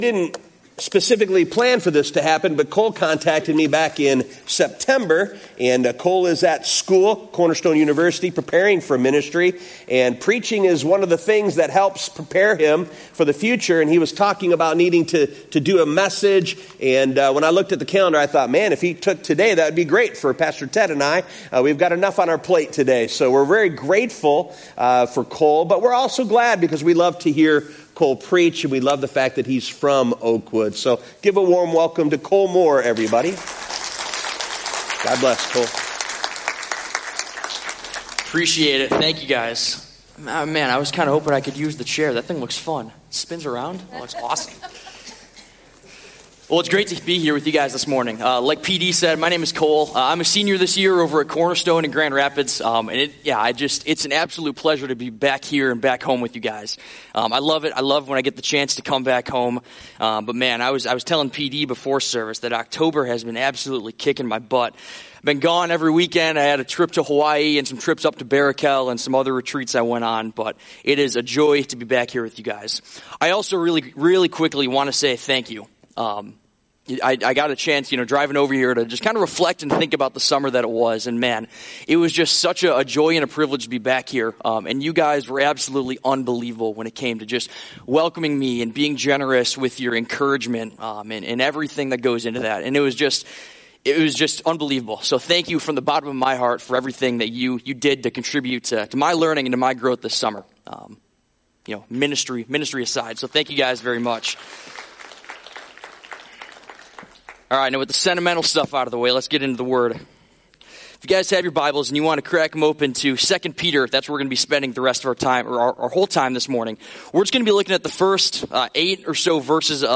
Notes: 1) No online message notes today. 2) We apologize that, due to technical difficulties, the first part of the service was cut off.